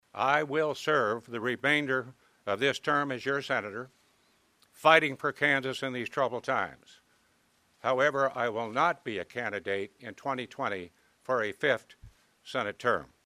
MANHATTAN — Senator Pat Roberts (R-Kan.) announced plans for his future during a news conference Friday morning at the Kansas Department of Agriculture headquarters in Manhattan.